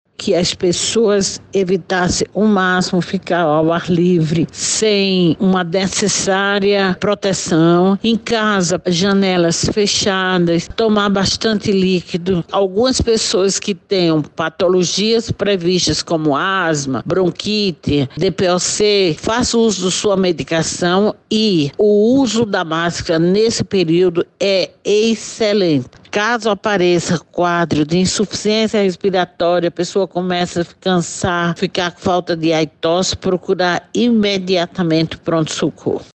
SONORA_DOUTORA-.mp3